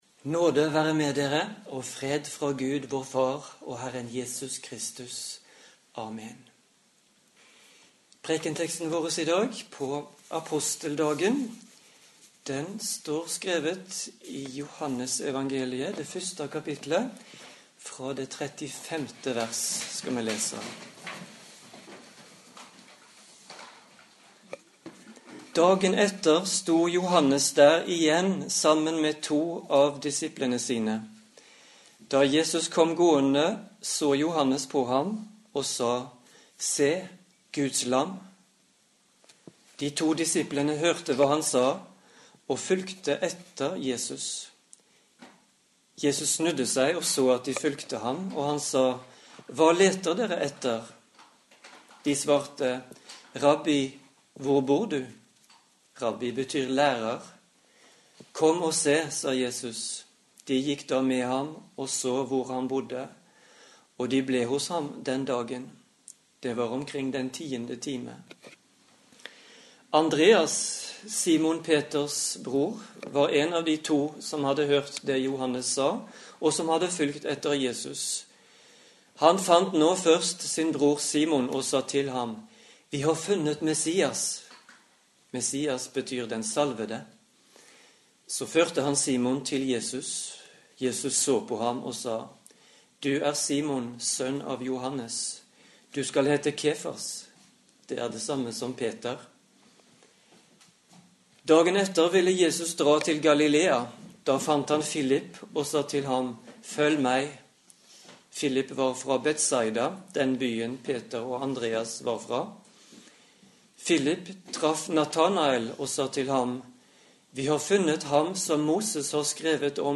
Preken på Aposteldagen